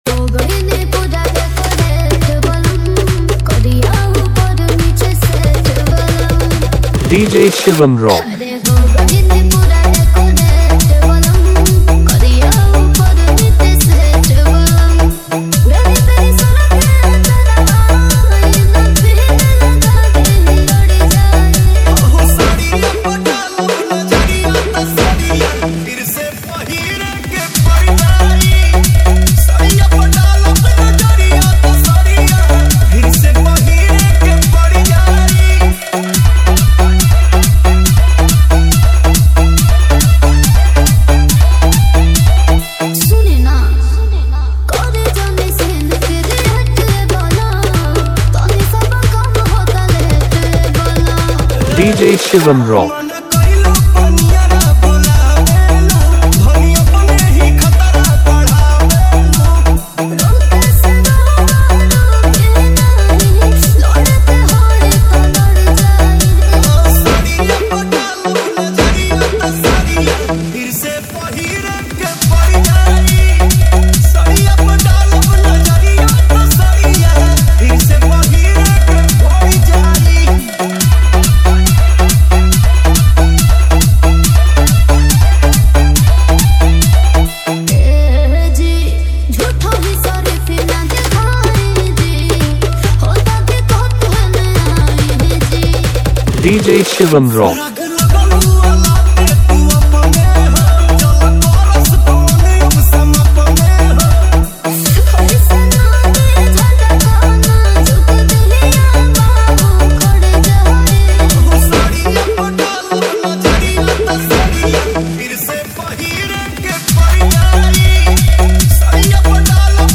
Bhojpuri Love DJ Remix